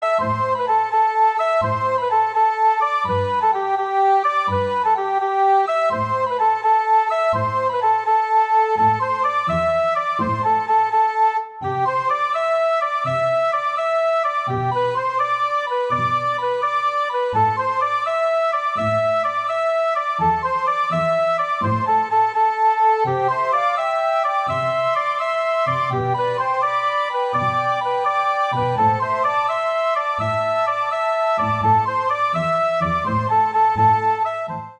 is a traditional Irish tune
round two adjacent chords, in this case A minor and G.